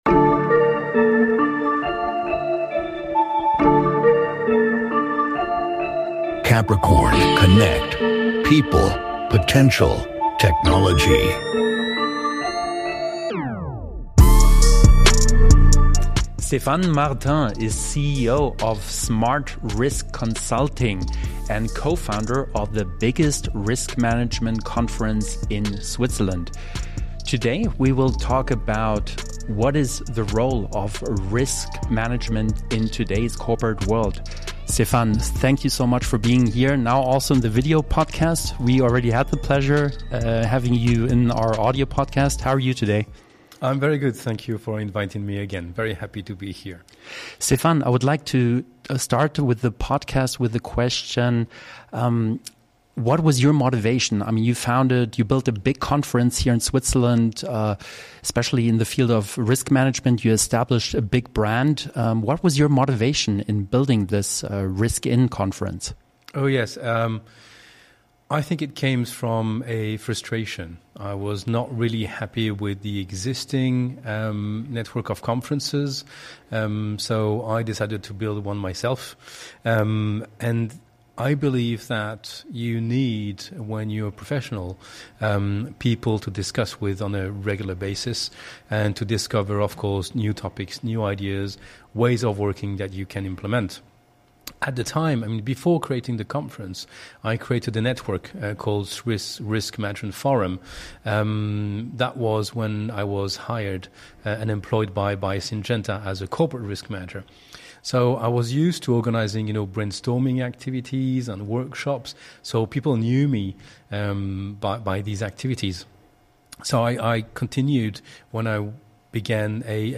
#85 - Interview